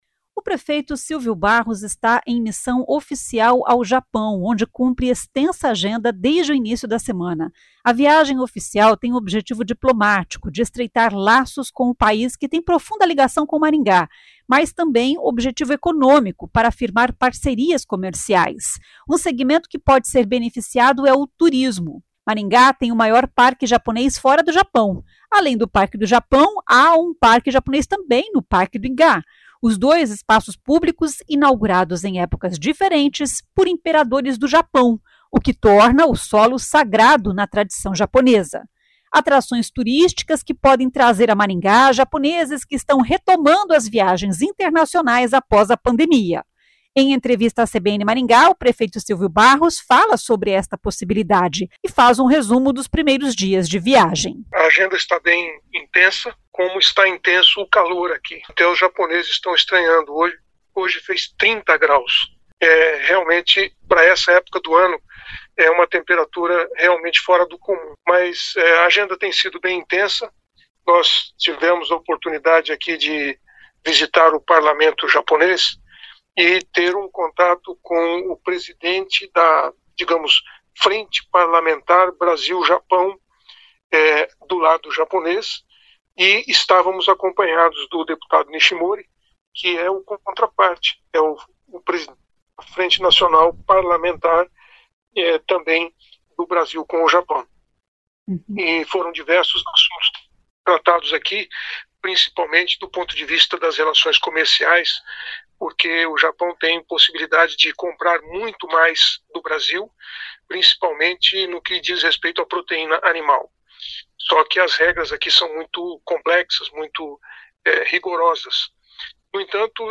Em entrevista à CBN Maringá, o prefeito Silvio Barros fala sobre esta possibilidade e faz um resumo dos primeiros dias de viagem.